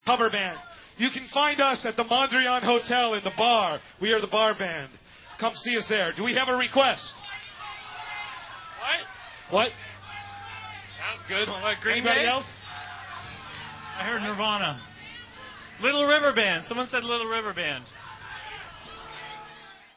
live bonus track